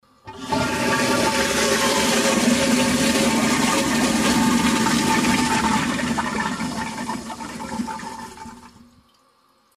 Звуки канализации
На этой странице собраны разнообразные звуки канализации: от тихого бульканья воды до резонанса в трубах.